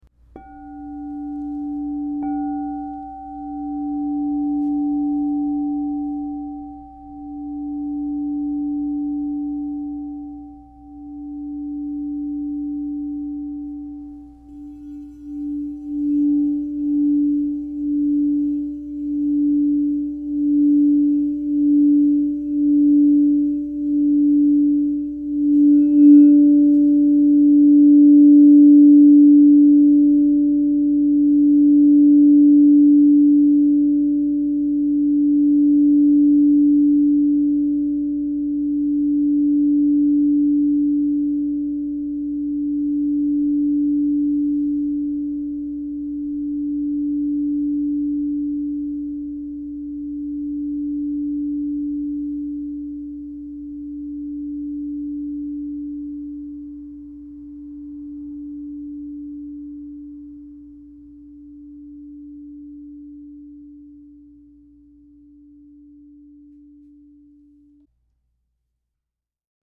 Crystal Tones® Supergrade Indium 8 Inch D Singing Bowl
Elevate your sound healing practice with the Crystal Tones® Supergrade Androgynous Indium 8 inch D Negative Singing Bowl, resonating at D -25 to harmonize body and spirit.
At 8 inches, this Supergrade bowl offers exceptional tonal clarity and resonance, making it ideal for both personal meditation and professional sound healing sessions.
Discover the power of this Crystal Tones® Supergrade 8″ alchemy singing bowl made with Androgynous Indium, Platinum (inside) in the key of D -25.
D
432Hz (-)